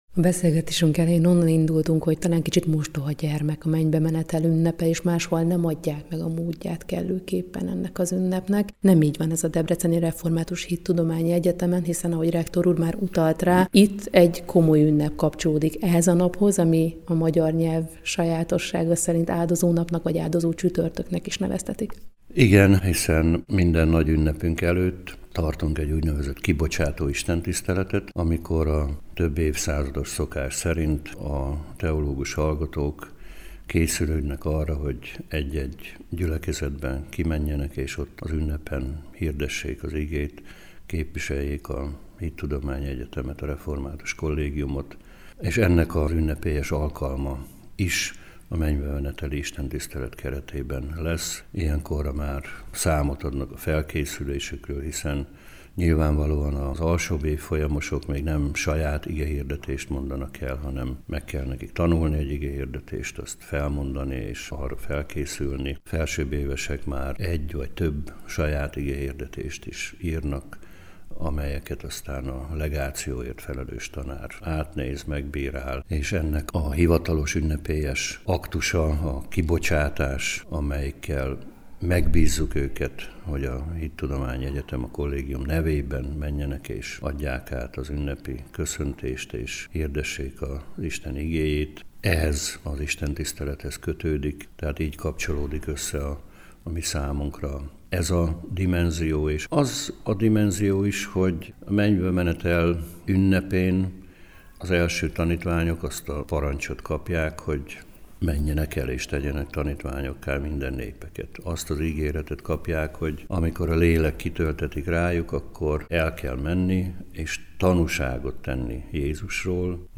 Mennybemenetel ünnepe - interjú Bölcskei Gusztávval